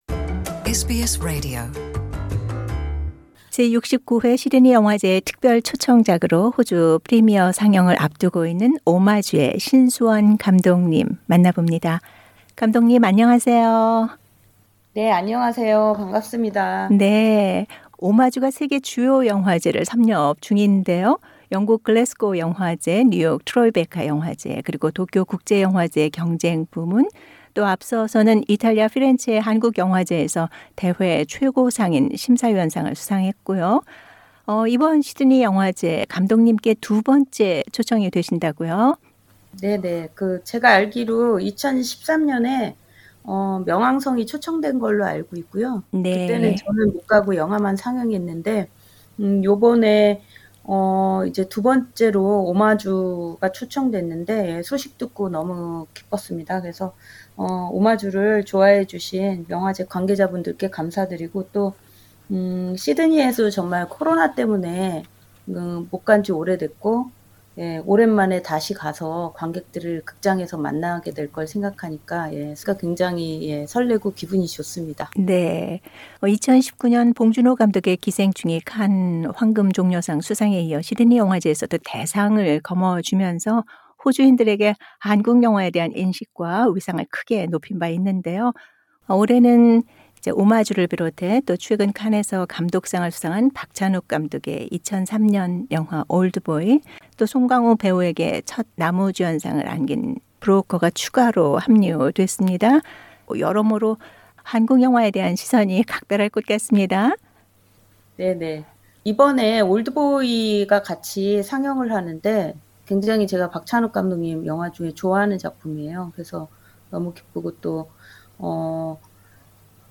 제69 회 시드니영화제 특별 초청작으로 호주 프리미어 상영을 앞두고 있는 ‘ 오마주’ 의 신수원 감독님 만나봅니다.